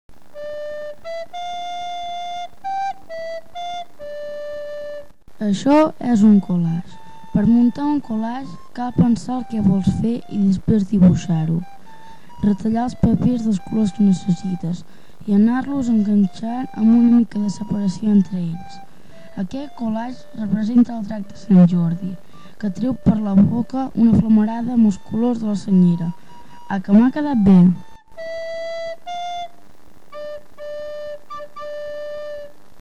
Cliqueu damunt la imatge i escolteu una altra vegada la locució, ara amb una música de fons.